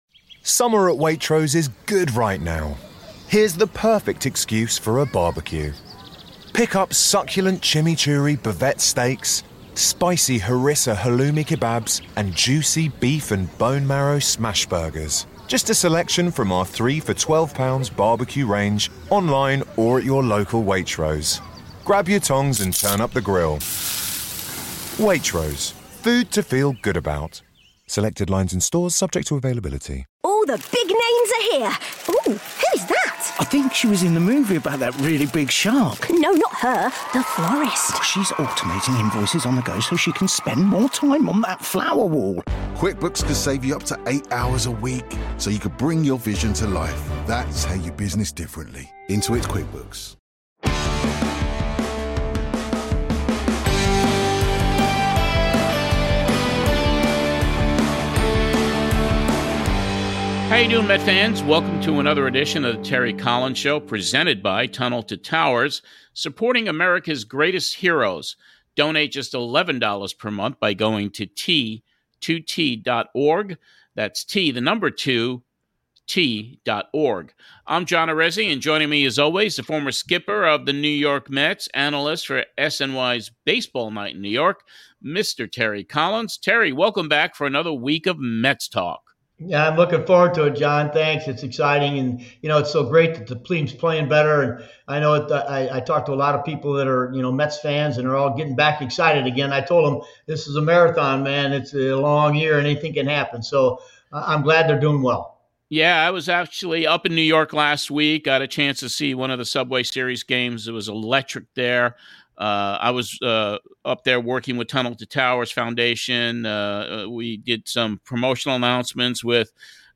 Two former star relievers of the NY Mets are the special guests this week.